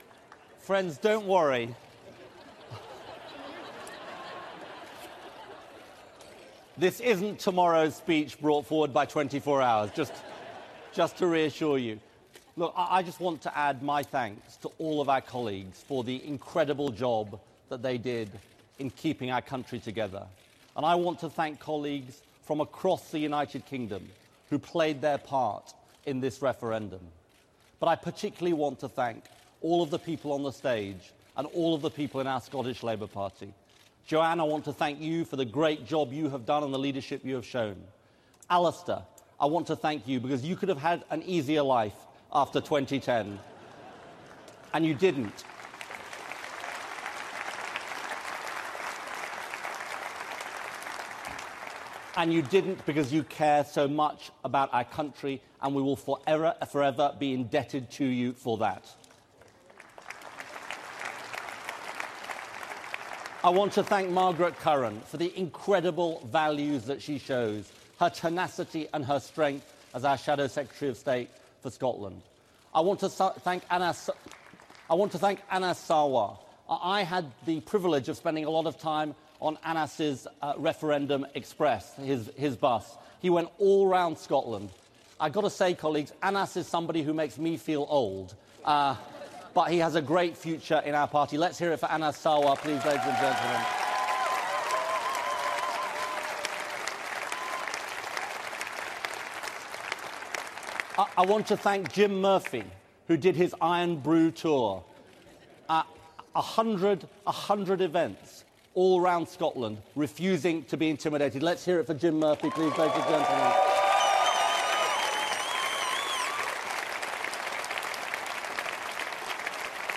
Labour conference, 22 September 2014